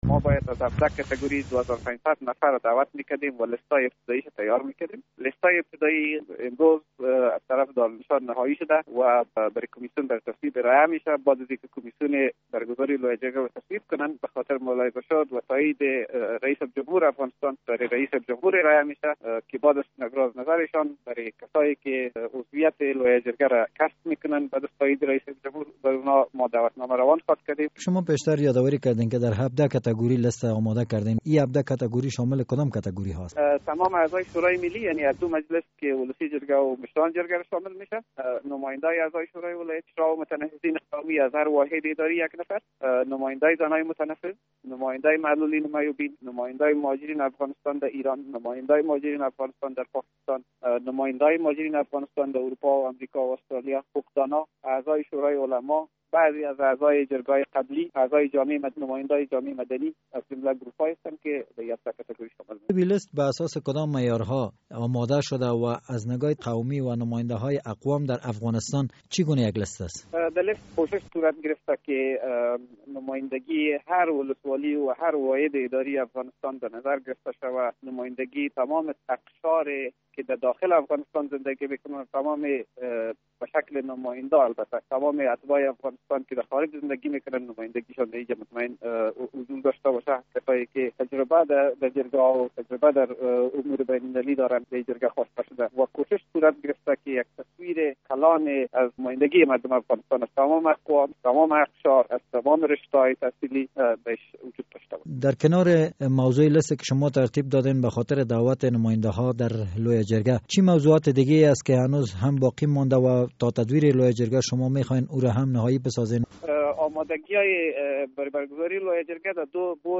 مصاحبه در مورد نهایی شدن فهرست اشتراک کننده گان جرگه مشورتی